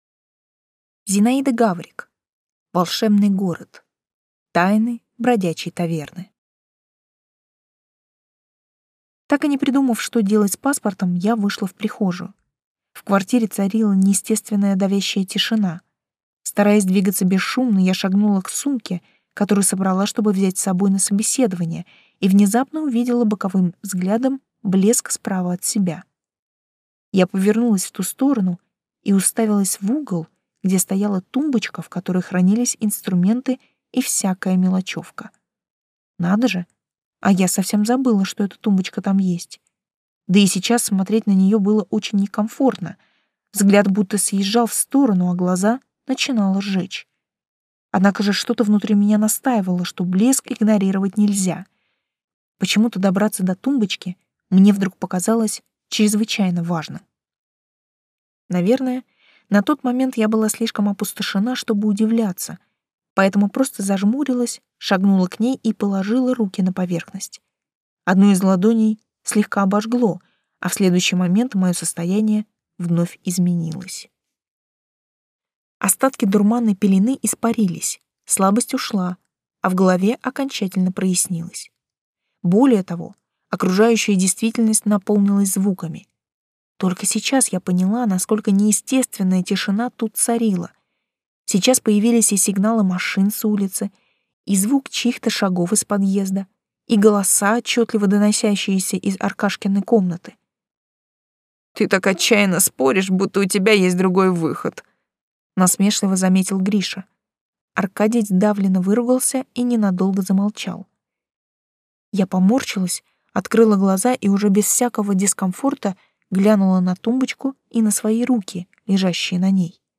Аудиокнига Волшебный город. Тайны бродячей таверны | Библиотека аудиокниг